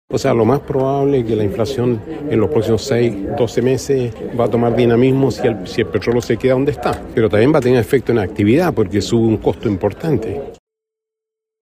En relación a esto, el expresidente del Banco Central, Vittorio Corbo, explicó que la actividad económica del país también podría verse afectada por estos vaivenes mundiales en el precio del petróleo.